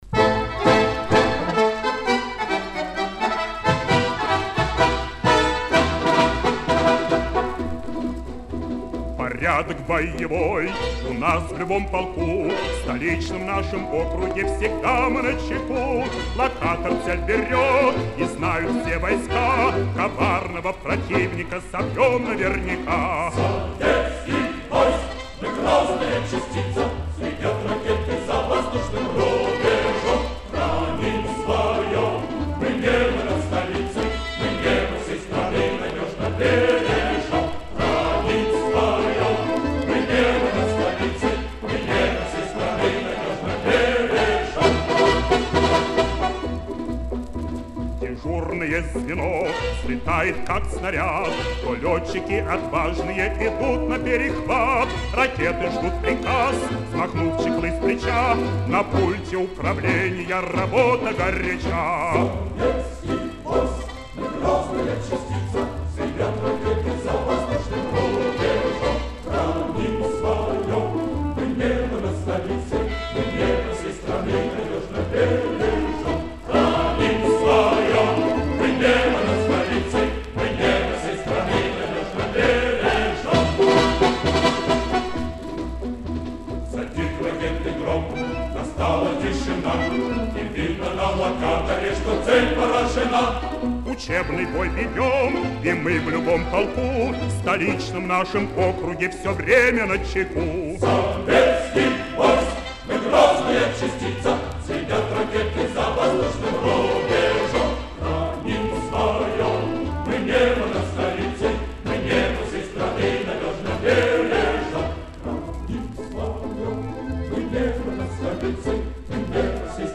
Марши
Песня в лучших традициях советских солдатских песен.